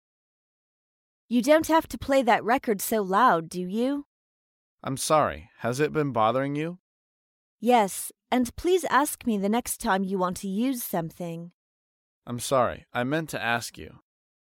在线英语听力室高频英语口语对话 第134期:抱怨噪音(2)的听力文件下载,《高频英语口语对话》栏目包含了日常生活中经常使用的英语情景对话，是学习英语口语，能够帮助英语爱好者在听英语对话的过程中，积累英语口语习语知识，提高英语听说水平，并通过栏目中的中英文字幕和音频MP3文件，提高英语语感。